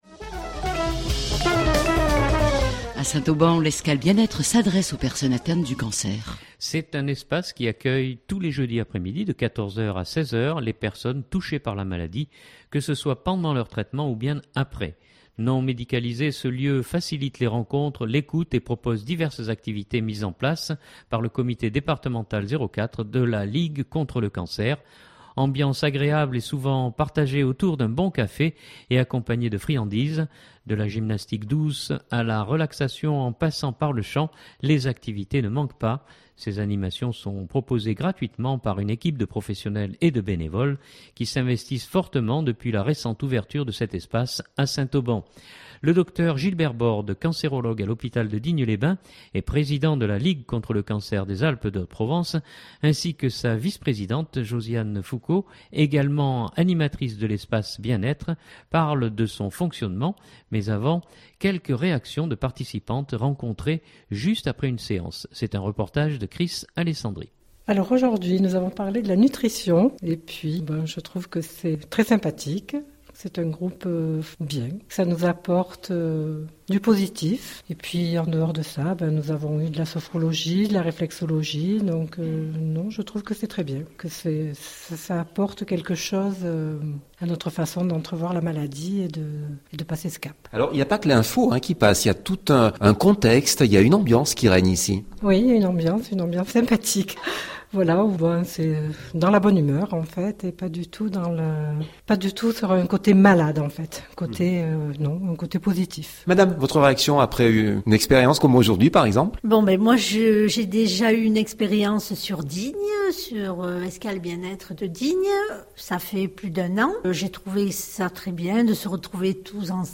Mais avant, quelques réactions de participantes rencontrées juste après une séance.